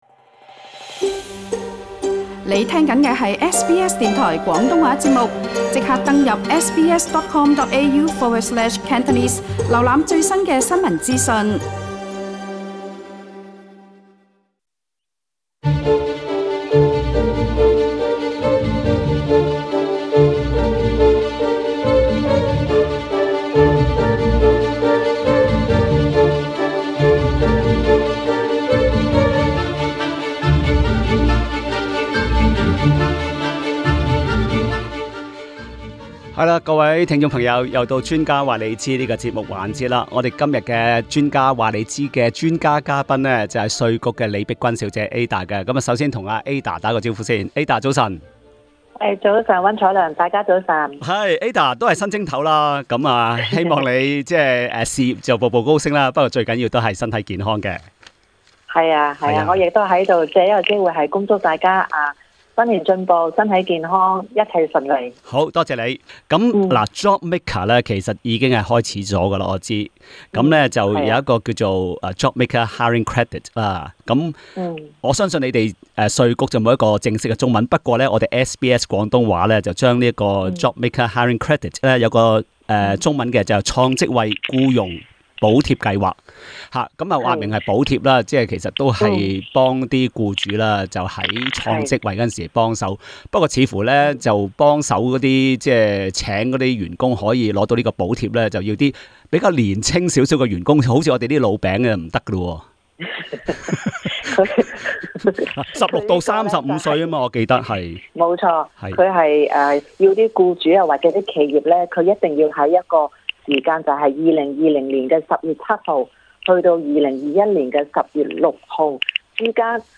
年輕員工分兩個組別 — 16 至 29 歲和 30 至 35 歲，合資格僱主將因應這兩組別而獲得不同的補助。 另外，很多聽眾打電話查問資產增值稅的問題。